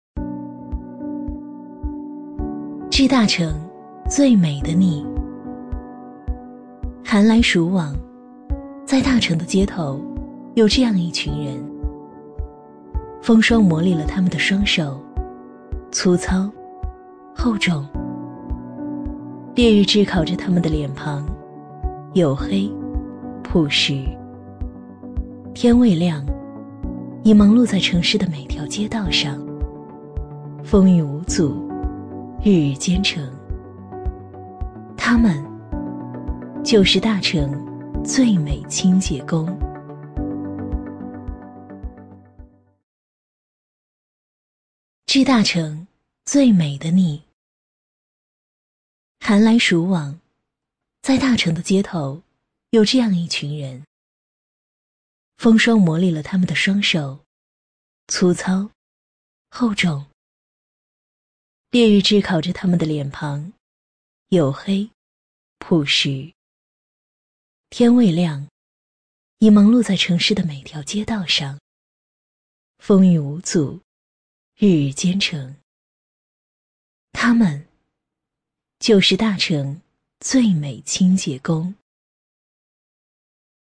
A类女50
【女50号旁白】稳重讲述-城市清洁公益
【女50号旁白】稳重讲述-城市清洁公益.mp3